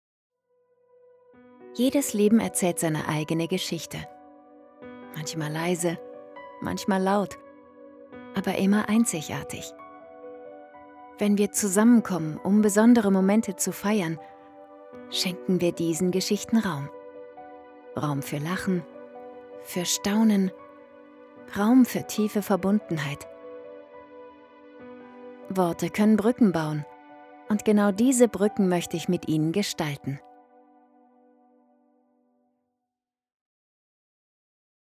Worte können Brücken bauen. - Klangbeispiel